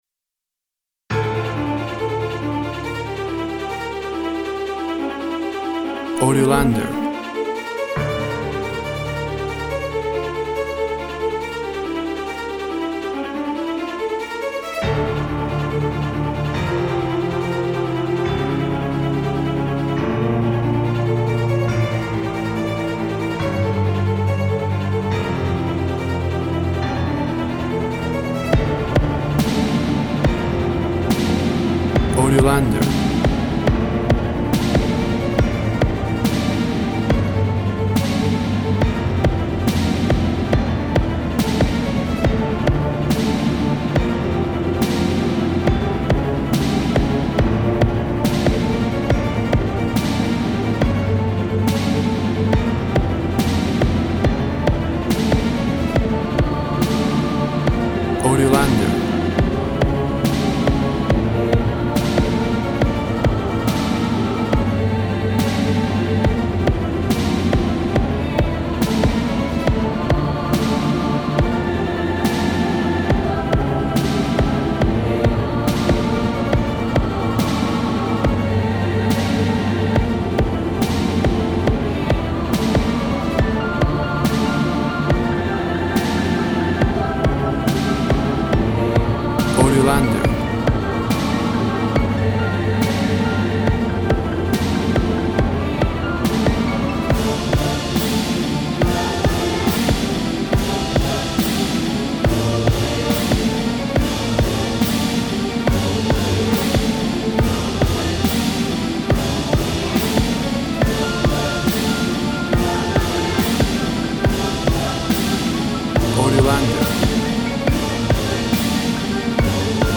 A modern orchestral downtempo soundtrack.
Tempo (BPM) 80